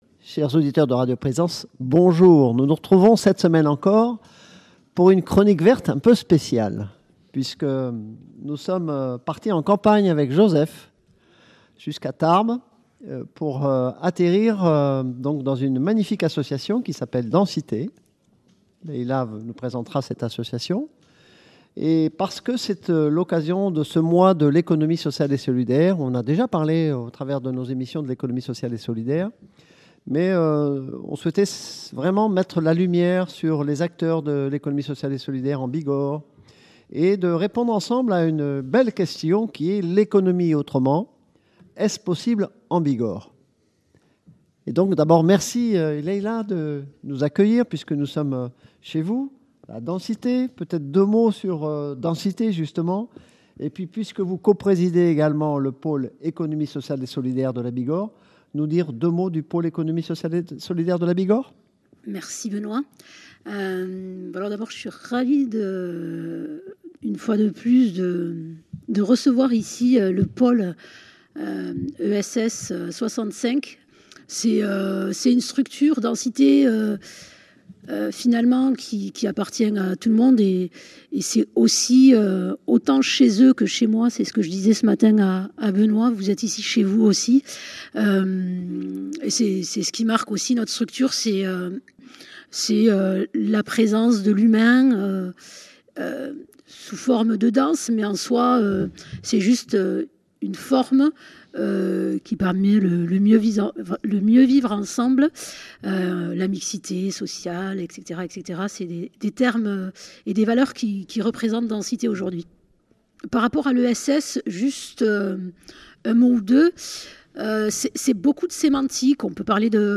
Table ronde autour du Pôle ESS